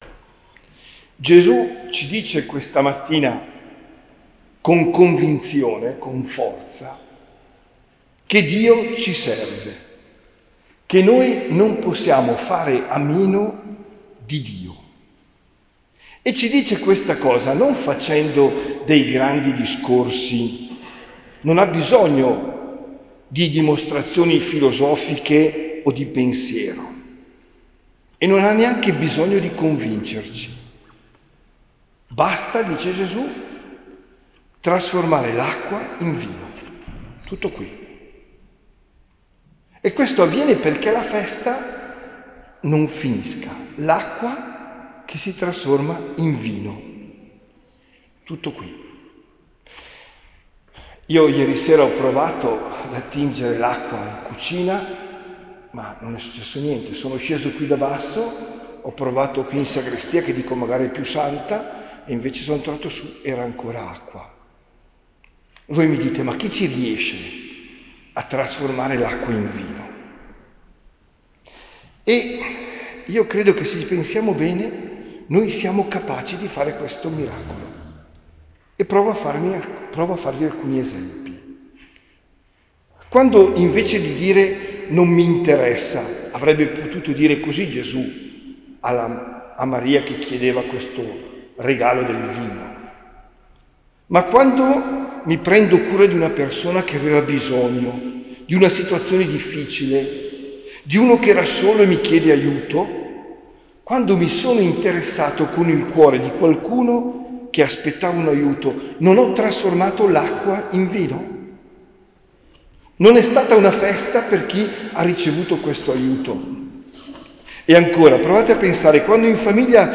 OMELIA DEL 19 GENNAIO 2025